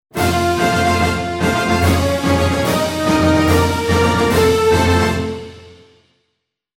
戦いに勝利したときやゲームをクリアしたときに鳴るジングルその１